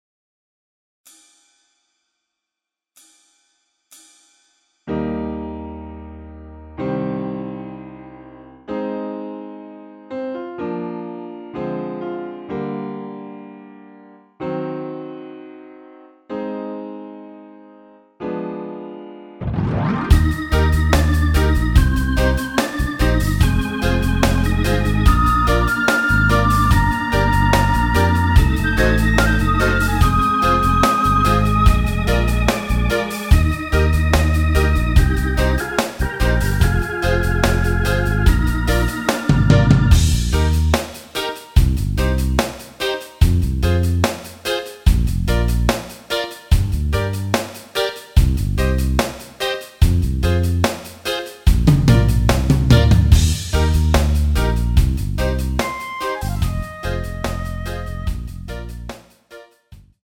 전주 없이 시작 하는곡이라 카운트 넣었으며 엔딩이 페이드 아웃이라 엔딩도 만들어 놓았습니다.(미리듣기 참조)
Eb
앞부분30초, 뒷부분30초씩 편집해서 올려 드리고 있습니다.
중간에 음이 끈어지고 다시 나오는 이유는